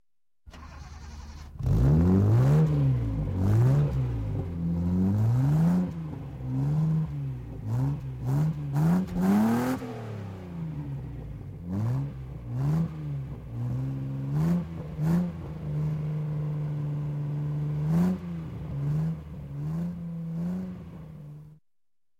Звуки выхлопа машин
Спорткар завели погазовали